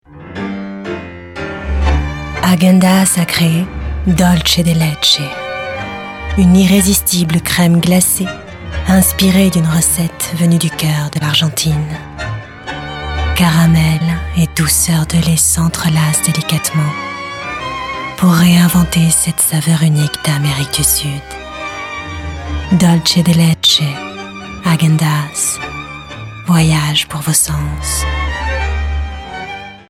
Comédienne voix off, voix sensuelle, mutine, jeune, institutionnelle, accent...
Sprechprobe: Werbung (Muttersprache):